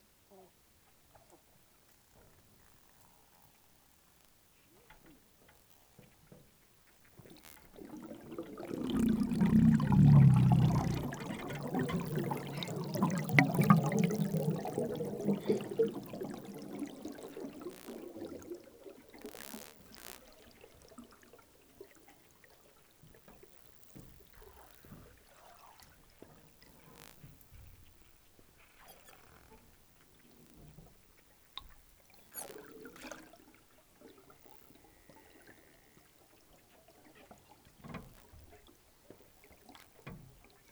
River dolphin spectrogram Spectrogram of another example of pink river dolphin sounds, and bubble sounds recorded on 24 July 2012